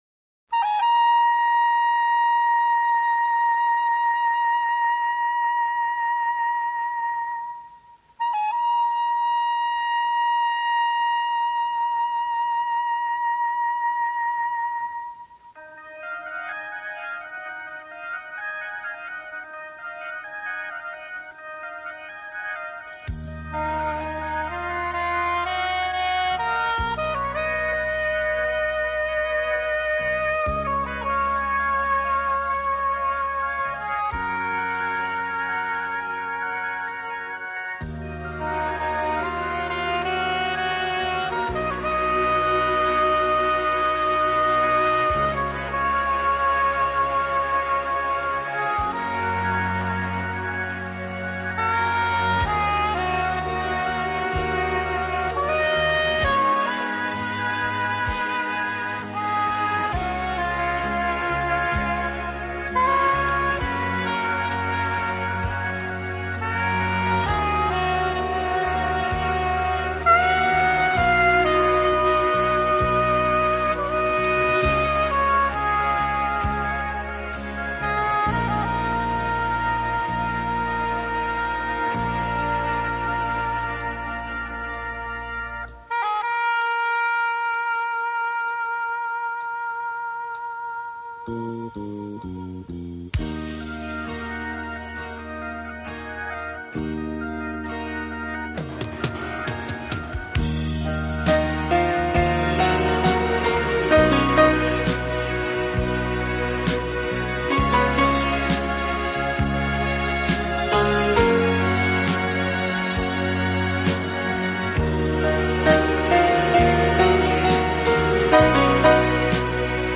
钢琴演奏版，更能烘托出复古情怀，欧美钢琴大师深具质感的演奏功力，弹指
本套CD全部钢琴演奏，